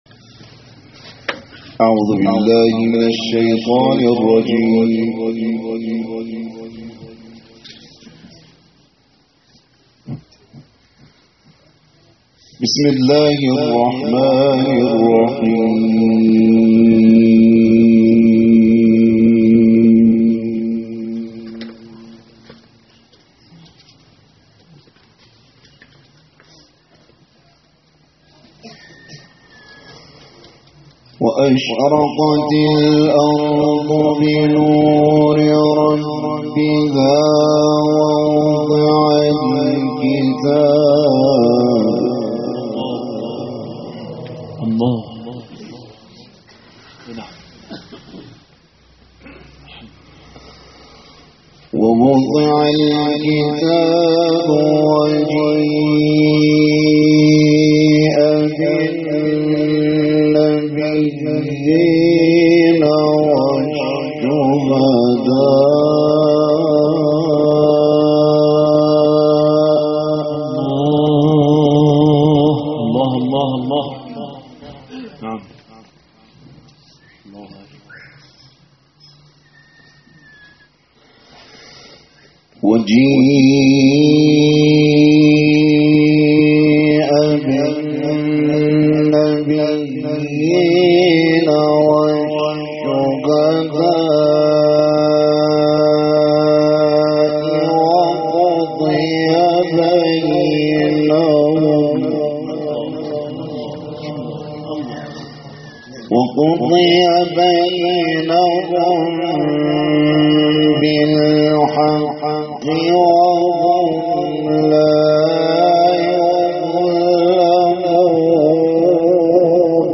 دانلود قرائت سوره زمر آیات 69 تا آخر و آیات ابتدایی سوره غافر